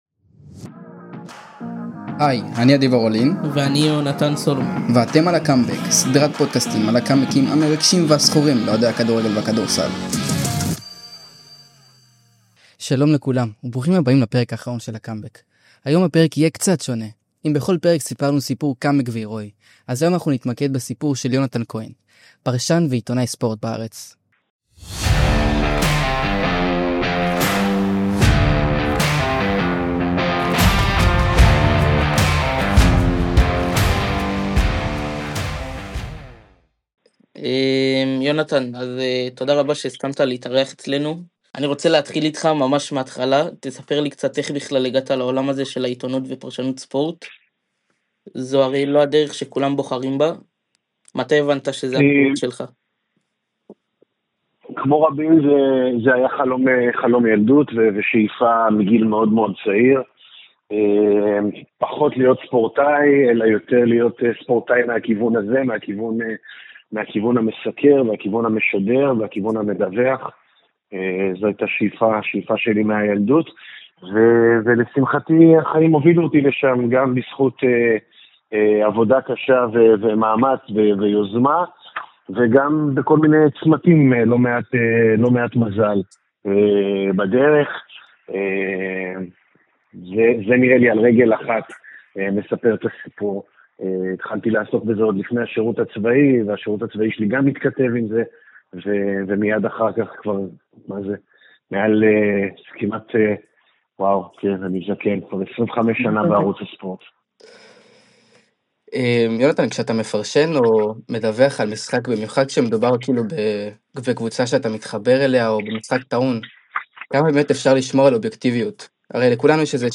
ריאיון